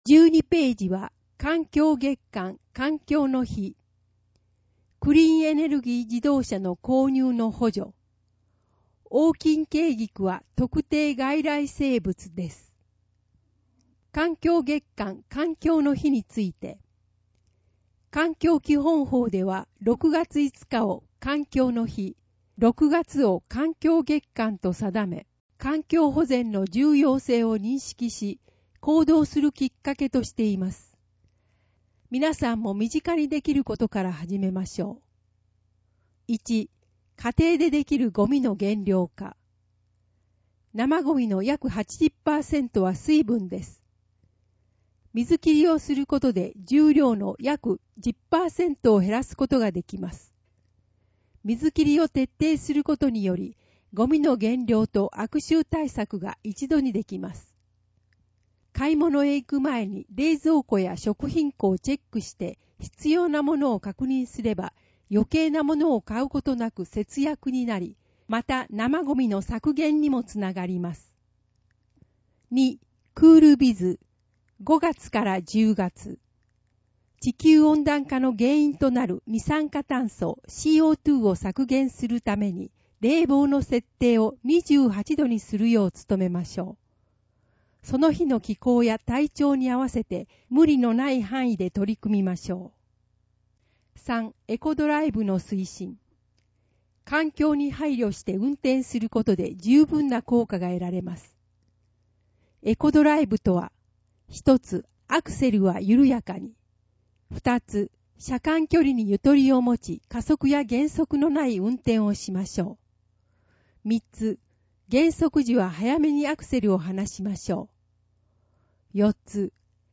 声の広報　広報たかはし5月号（199）